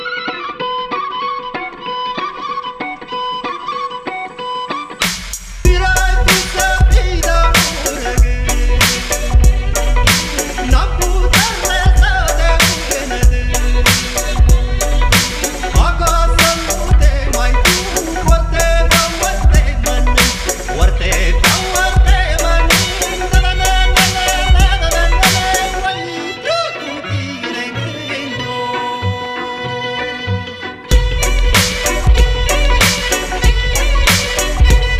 Жанр: Электроника / Фолк